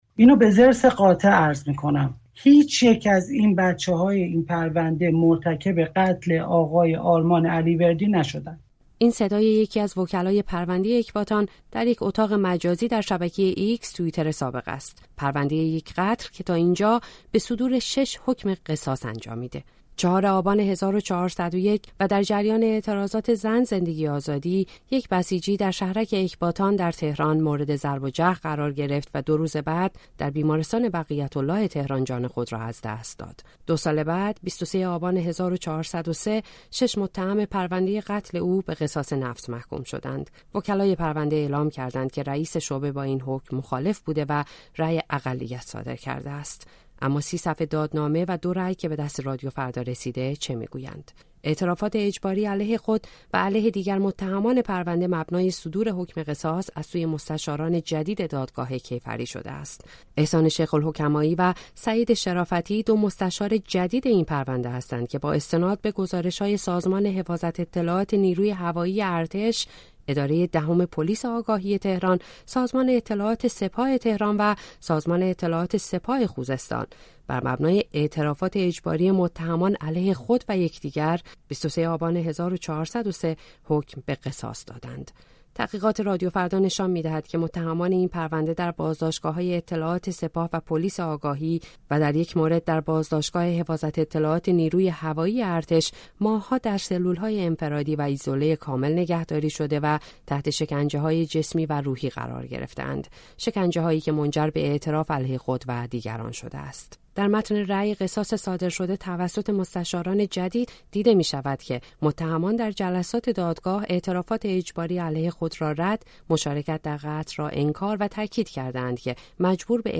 حکم پرونده اکباتان در گفت‌وگو با عضو اتحادیه بین‌المللی وکلای دادگستری